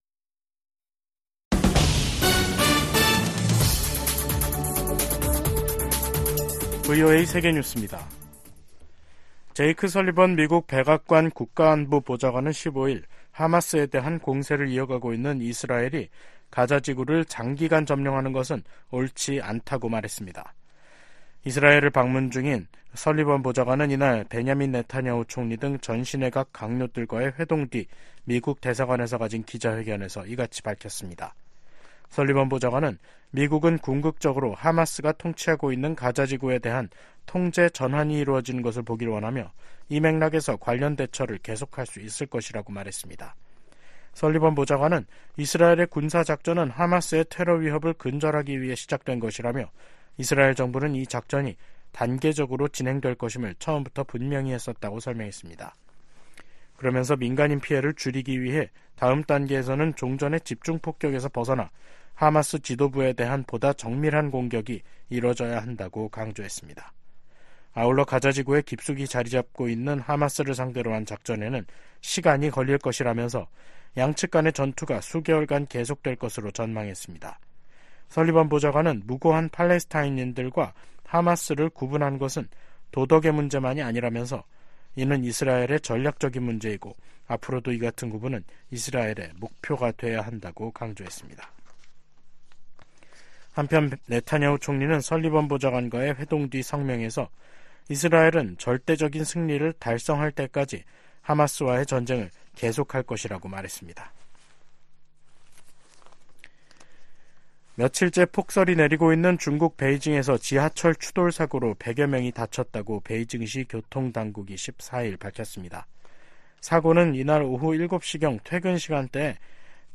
VOA 한국어 간판 뉴스 프로그램 '뉴스 투데이', 2023년 12월 15일 3부 방송입니다. 내년도 회계연도 미국 국방 정책의 방향과 예산을 설정한 국방수권법안이 의회를 통과했습니다. 미국 정부가 한반도 완전한 비핵화 목표에 변함 없다고 확인했습니다. 영국 상원이 북한의 불법적인 무기 개발과 북러 간 무기 거래, 심각한 인권 문제 등을 제기하며 정부의 대응을 촉구했습니다.